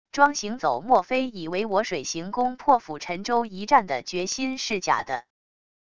庄行走莫非以为我水行宫破釜沉舟一战的决心是假的wav音频